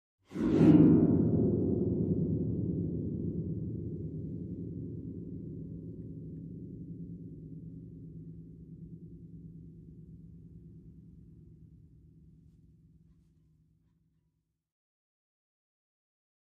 Harp, Fast And Short Gliss, Type 3 - Scary Version Of Type 1